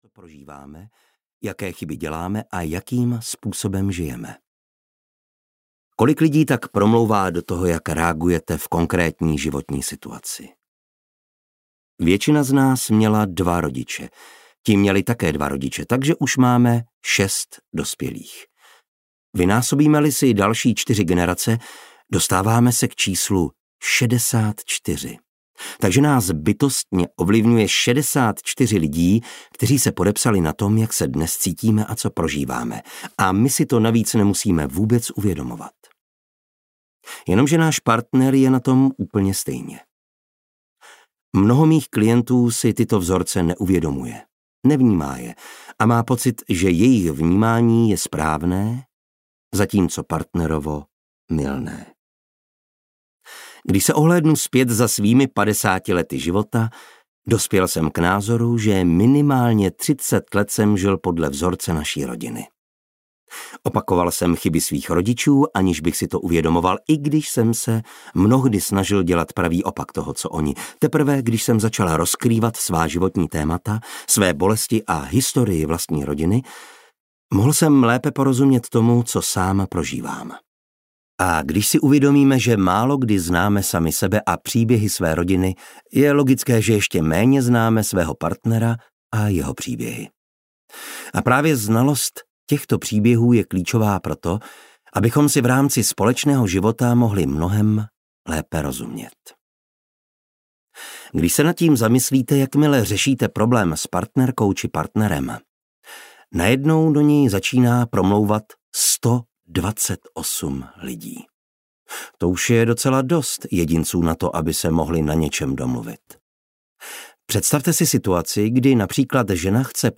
Kniha pro partnery audiokniha
Ukázka z knihy